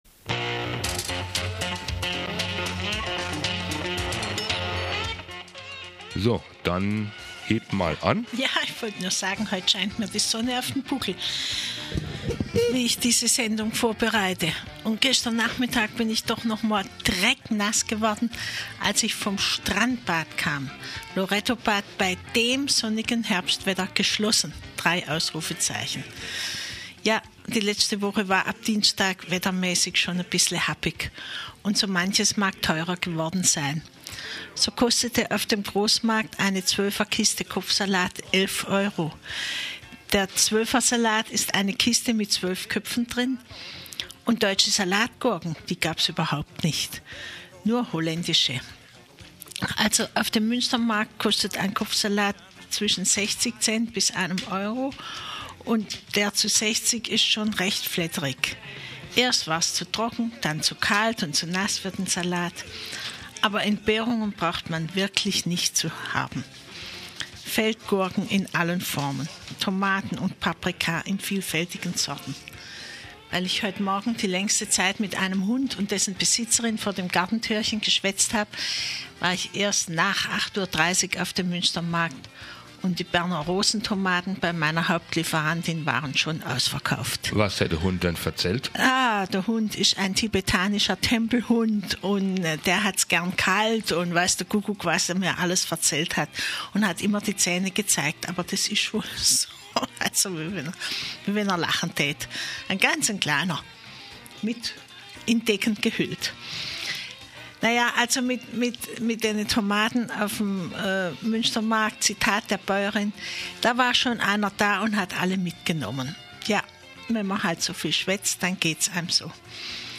punkt12 Mittagsmagazin mit Veranstaltungshinweisen für das Dreyeckland Sendezeit: Montag bis Freitag von 12 bis 13 Uhr Fokusiert auf unser Sendegebiet hört ihr Mittags zwischen 12 und 13 Uhr aktuelle Interviews und Portraits, Beiträge, Nachrichten, Reszensionen, Veranstaltungs- und Programmhinweise aus und für die subkulturellen Scenen des Dreyecklands.